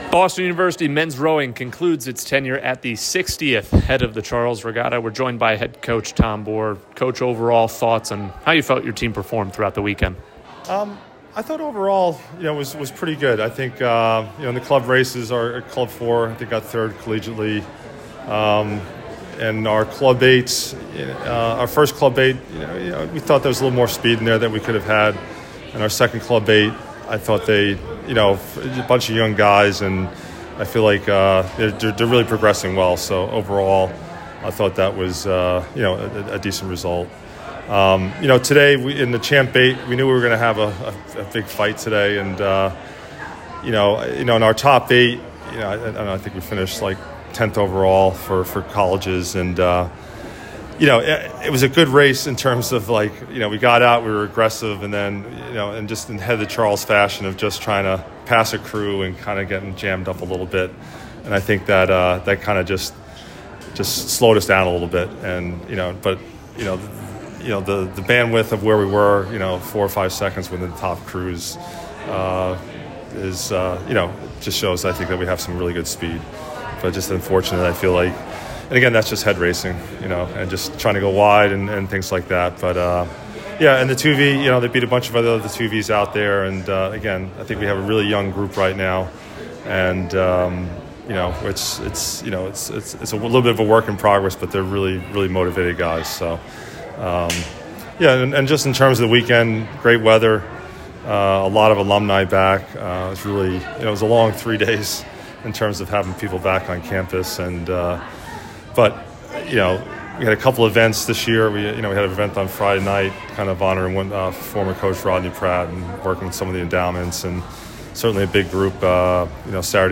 Head of the Charles Interview